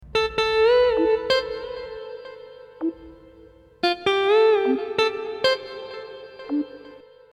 Вложения Оригинал.mp3 Оригинал.mp3 290,9 KB · Просмотры: 794 найденная гитара.mp3 найденная гитара.mp3 286,9 KB · Просмотры: 1.316